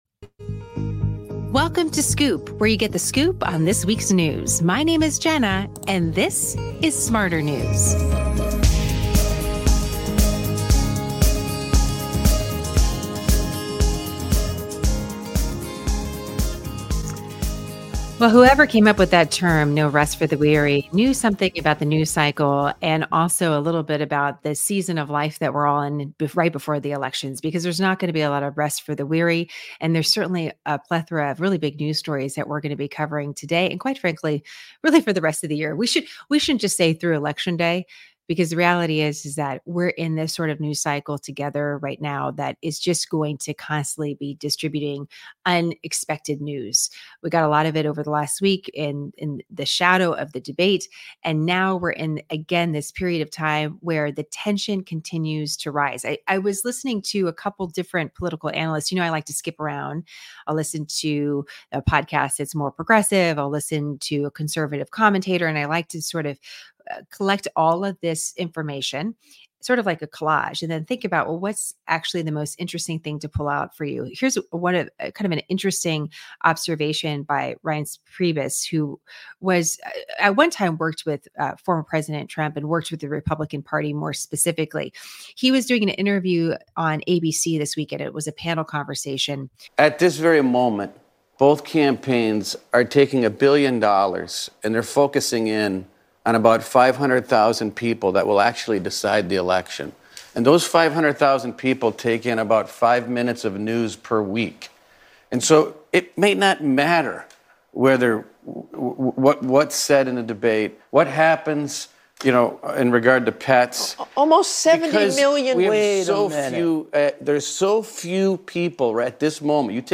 ✓ 12:00: A soundbite from law enforcement.
✓ 21:00: A clip from the recent presidential debate regarding the economy.